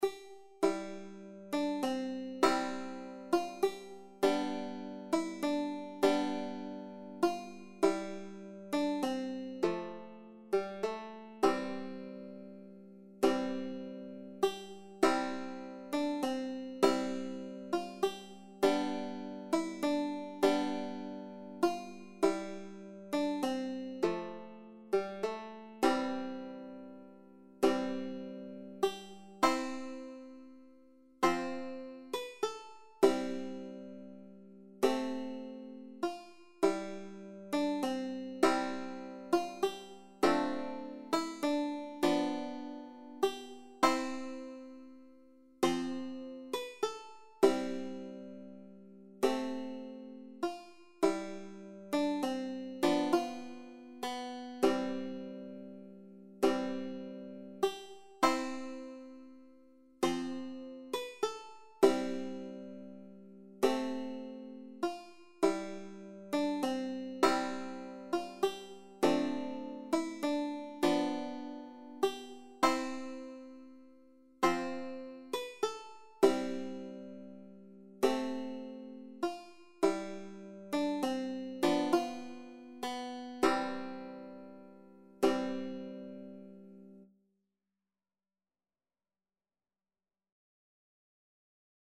Traditional Trad. The South Wind Banjo version
3/4 (View more 3/4 Music)
C major (Sounding Pitch) (View more C major Music for Banjo )
Banjo  (View more Intermediate Banjo Music)
Traditional (View more Traditional Banjo Music)
Irish